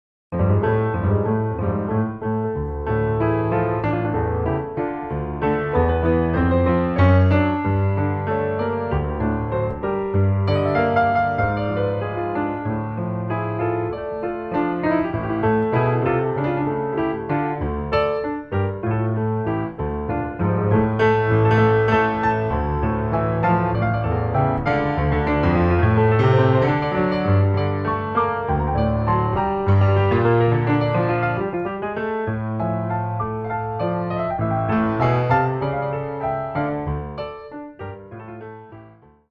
New Orleans style piano solo
sombre yet hopeful